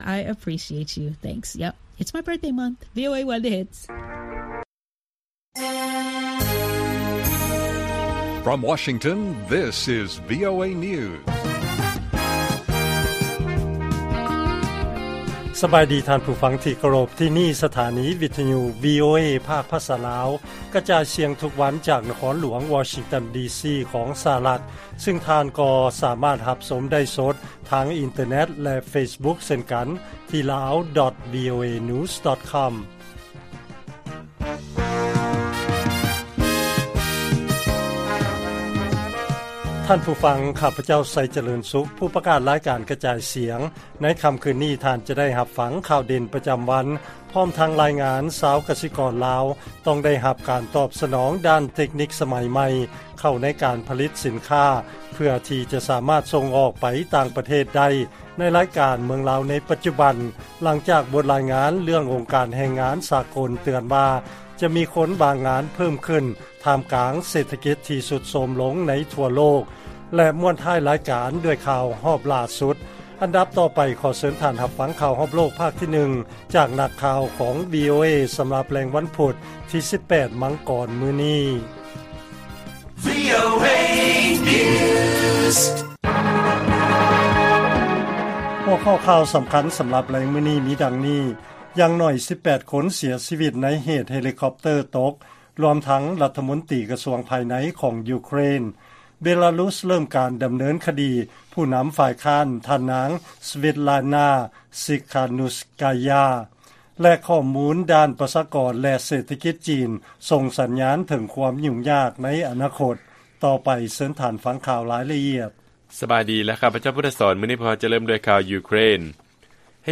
ລາຍການກະຈາຍສຽງຂອງວີໂອເອ ລາວ: ຢ່າງໜ້ອຍ 18 ຄົນ ເສຍຊີວິດ ໃນເຫດເຮລິຄັອບເຕີຕົກ, ລວມທັງລັດຖະມົນຕີກະຊວງພາຍໃນ ຢູເຄຣນ